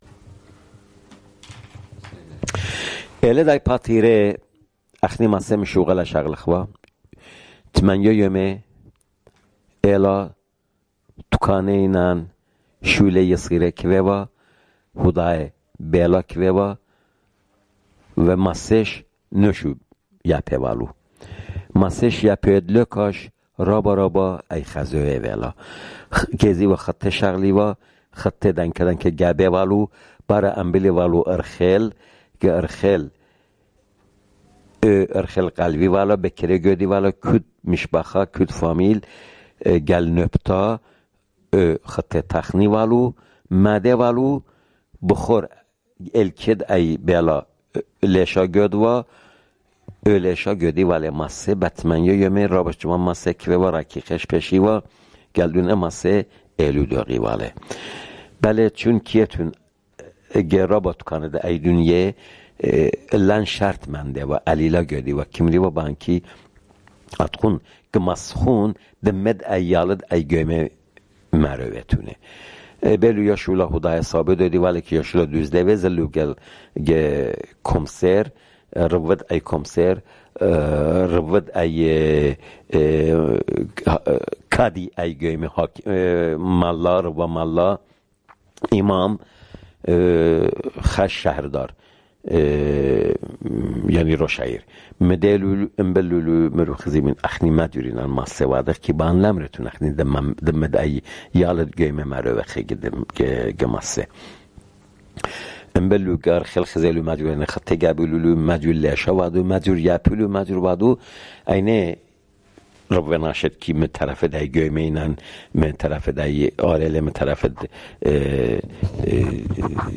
Urmi, Jewish: Passover